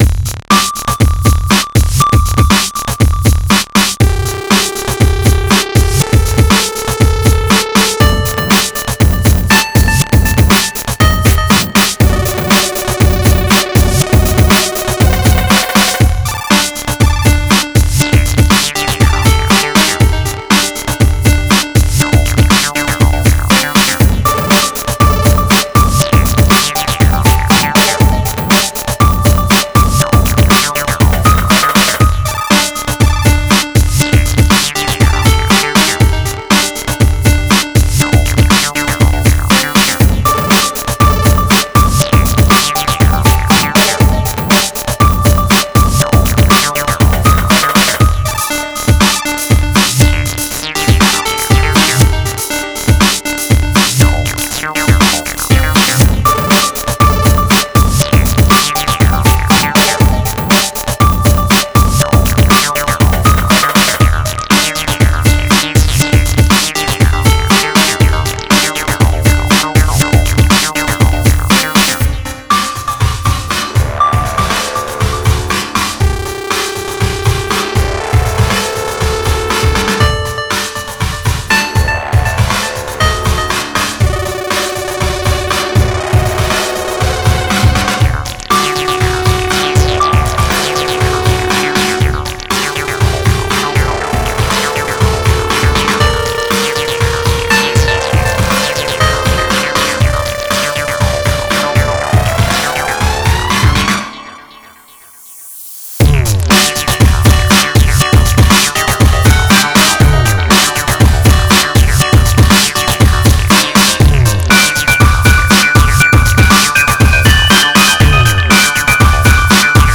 HQ Cyberpunkish tracks I composed (OGG format)